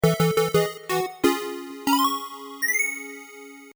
アイキャッチやジングルに使えそうな短いフレーズ
ゲームクリア_3 シューティングゲームのステージクリア